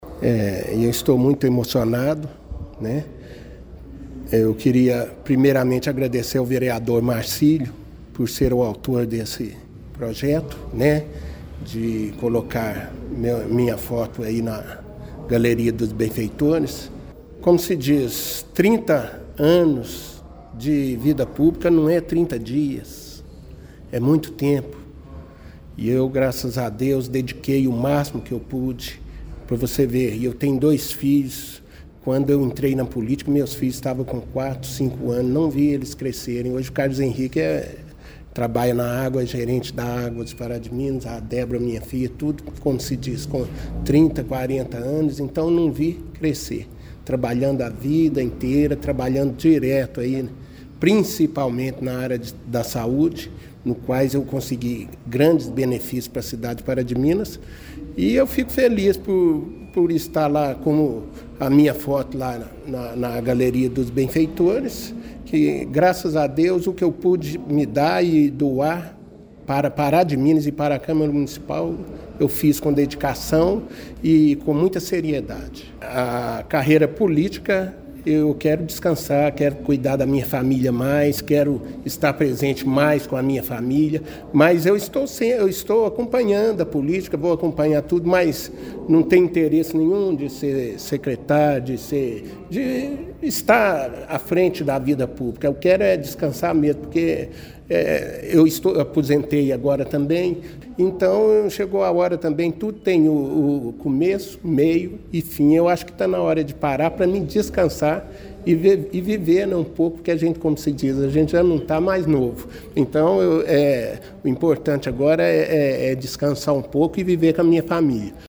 A Câmara Municipal de Pará de Minas realizou na tarde desta terça-feira, 19 de novembro, a 40ª reunião semanal ordinária no exercício de 2024.